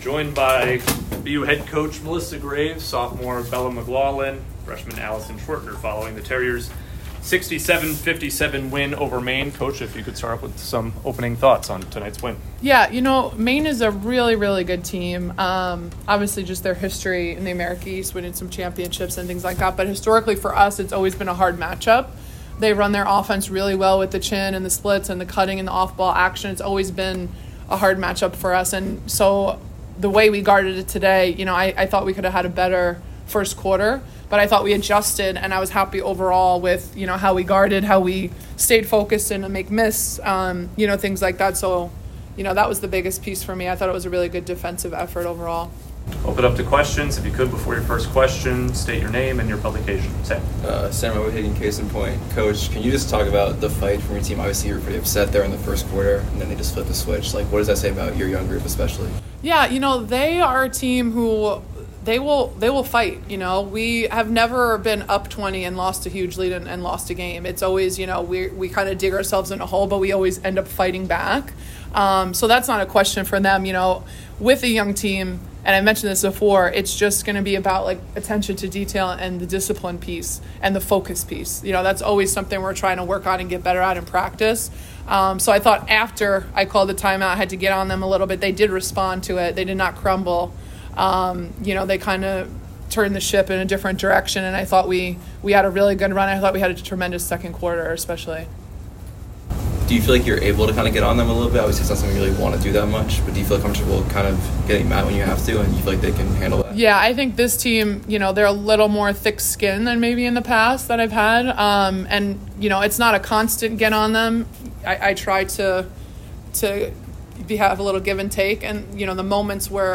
WBB_Maine_Postgame.mp3